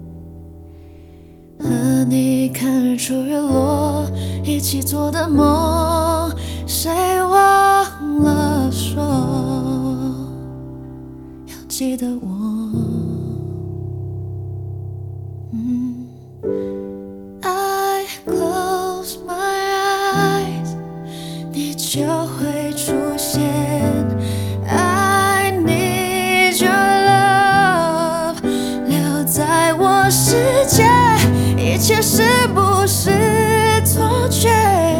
Жанр: Поп
# Mandopop